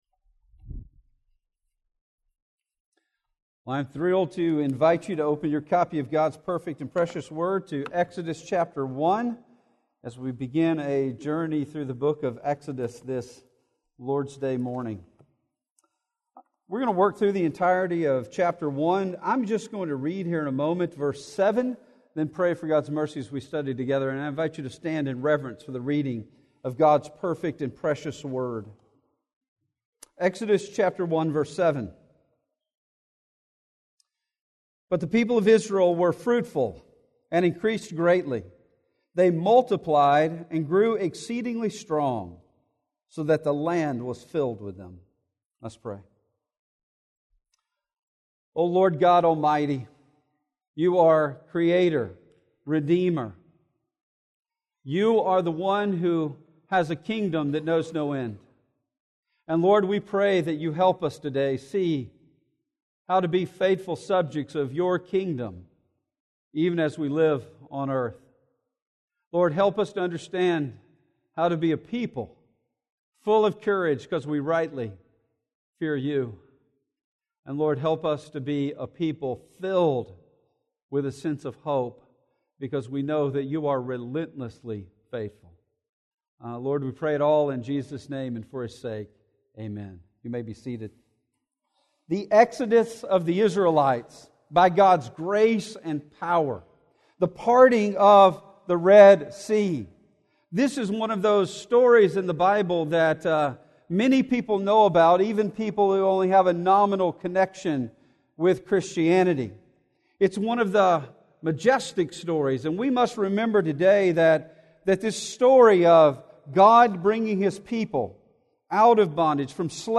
In this opening sermon of our Exodus series we see God's relentless commitment to keeping his promise to his people, and how that helps us to wisely fear.